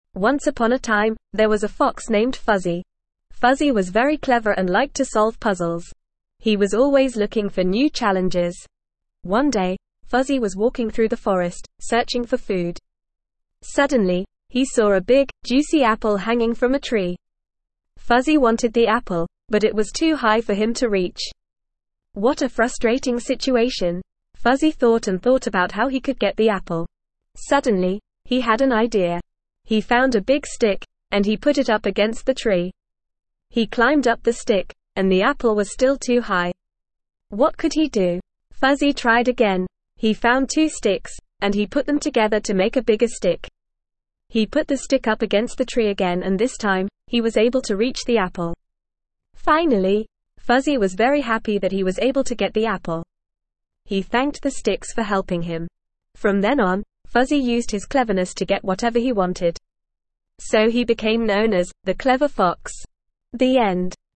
Normal
ESL-Short-Stories-for-Kids-NORMAL-reading-The-Clever-Fox.mp3